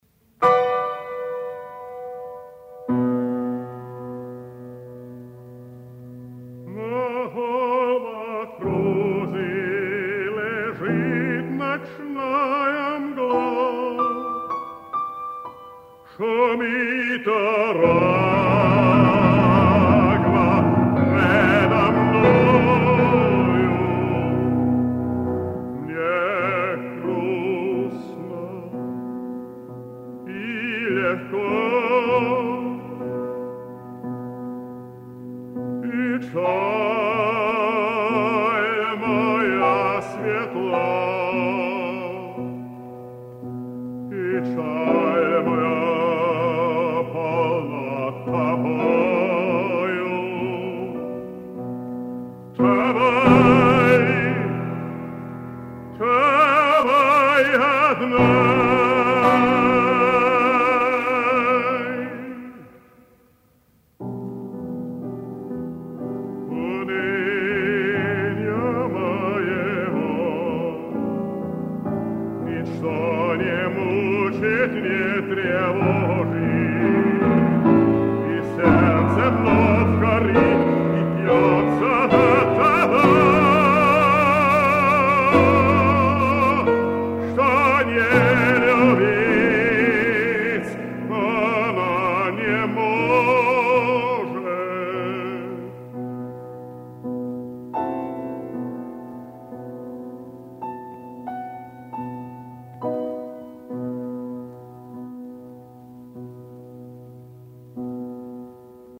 100 лет со дня рождения эстонского певца (баритон), Народного артиста СССР, Тийта Яновича Куузика !!!!!!!